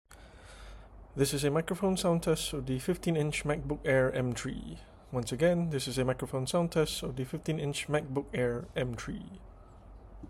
Speaking of audio, the built-in microphone sounds absolutely amazing. It can capture such vocal clarity and depth as if I’m using a standalone condenser microphone.
The audio recording above is raw, unedited, and recorded straight from the MacBook Air.